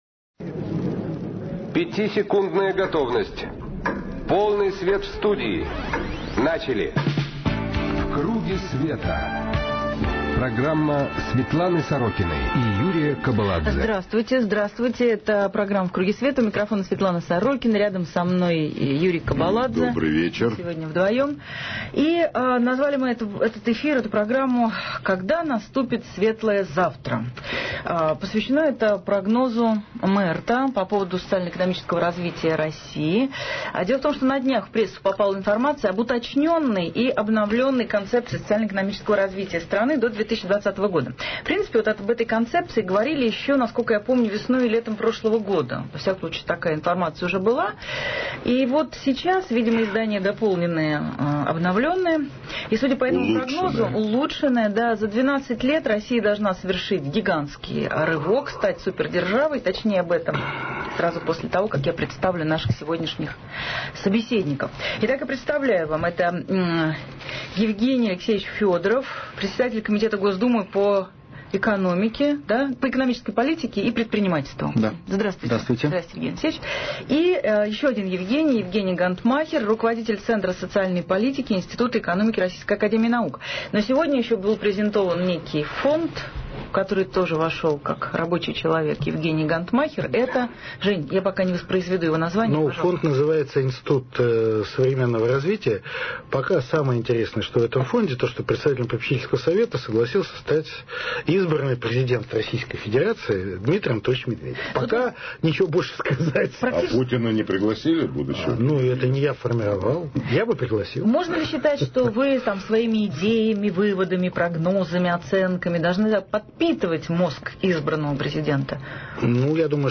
В КРУГЕ СВЕТА программа Светланы Сорокиной на радио «Эхо Москвы» соведущий – Юрий Кобаладзе 18 марта 2008 г. Когда наступит светлое завтра? (планы МЭРТ до 2020 года) Гости – Евгений Гонтмахер, Евгений Фёдоров.